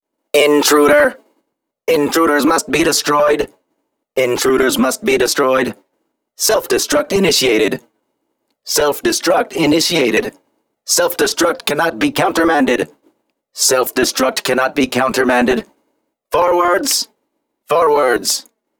robot.wav